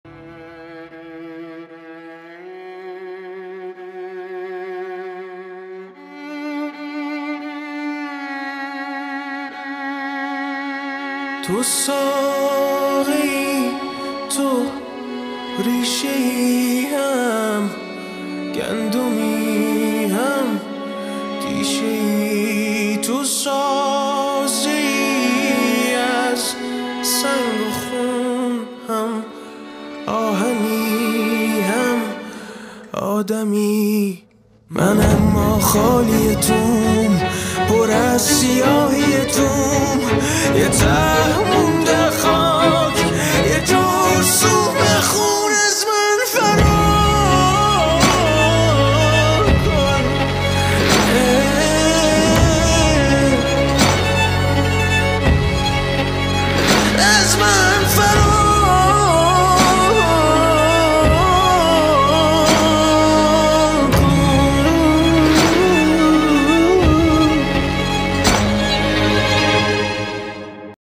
• موسیقی پاپ ایرانی